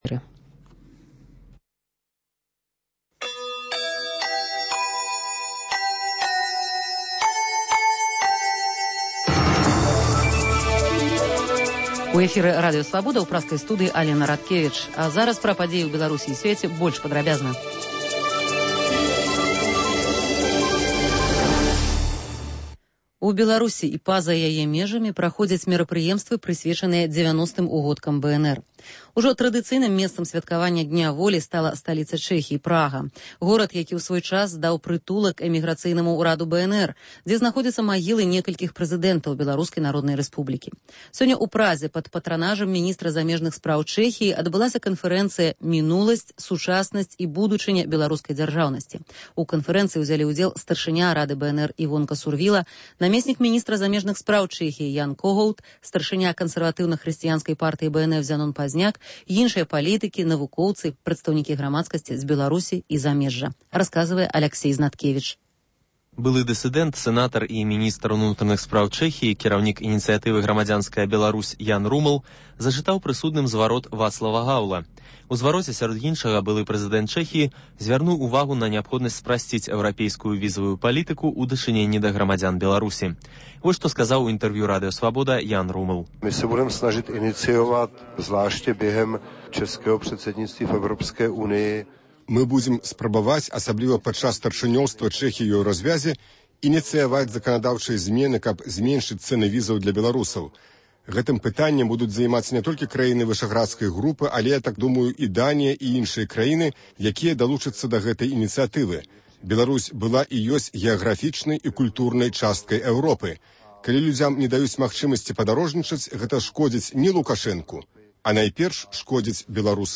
Інфармацыйны блёк: навіны Беларусі і сьвету.